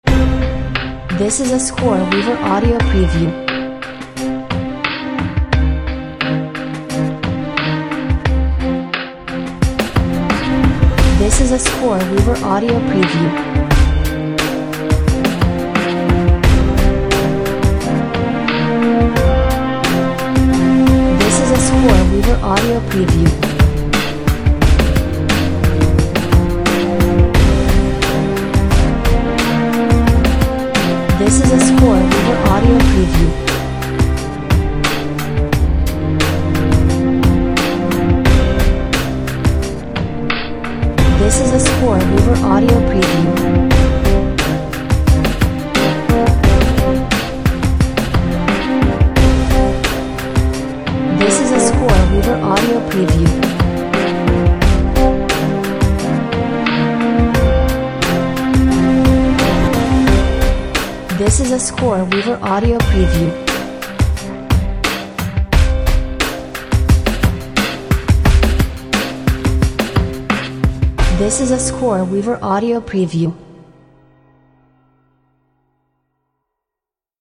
Features deep strings, bells, brass and a lot of tension…